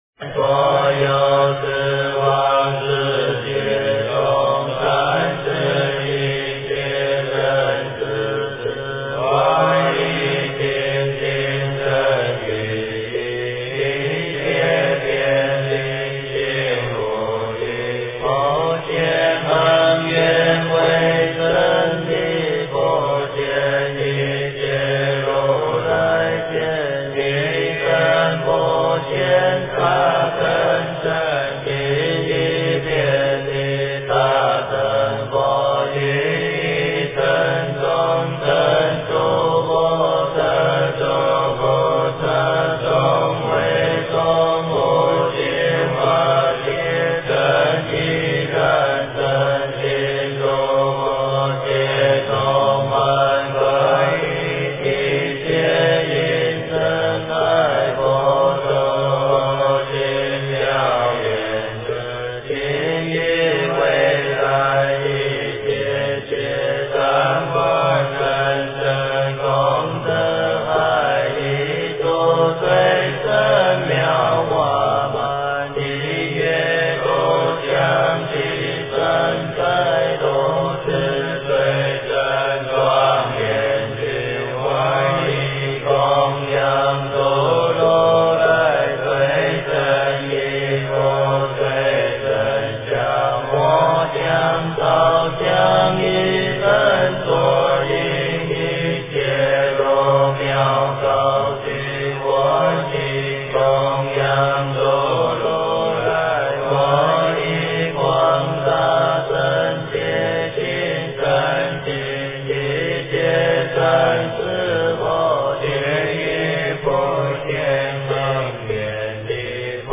普贤行愿品--僧团 经忏 普贤行愿品--僧团 点我： 标签: 佛音 经忏 佛教音乐 返回列表 上一篇： 早课--辽宁万佛禅寺 下一篇： 观世音菩萨白佛言--佛光山梵呗团 相关文章 四字弥陀--海涛法师 四字弥陀--海涛法师...